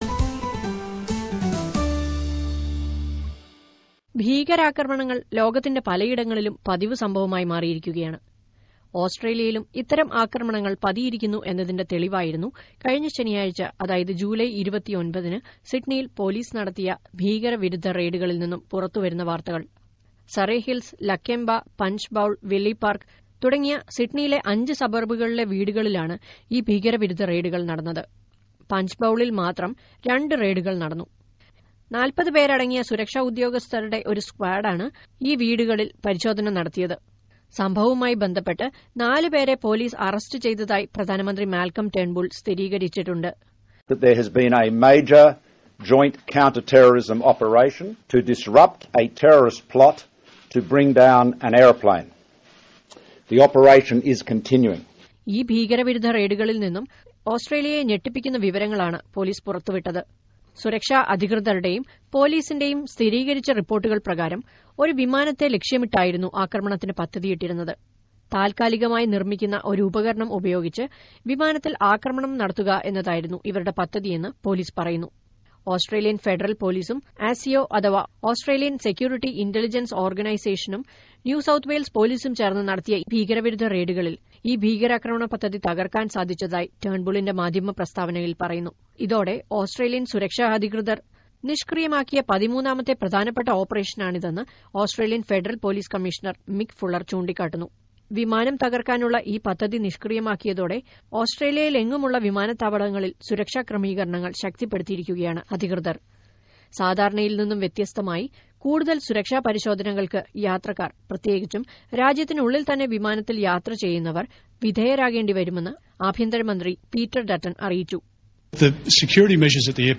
ഇതുമൂലം ഏറെ നേരത്തെ കാത്തിരിപ്പാണ് വിമാനത്താവളങ്ങളിൽ. ഈ ദിവസങ്ങളിൽ രാജ്യത്തെ മൂന്ന് വിമാനത്താവളങ്ങൾ വഴി യാത്ര ചെയ്ത ചില മലയാളികൾ അവരുടെ അനുഭവങ്ങൾ എസ് ബി എസ് മലയാളത്തോട് പങ്കുവയ്ക്കുന്നത് കേൾക്കാം മുകളിലെ പ്ലേയറിൽ നിന്ന് ...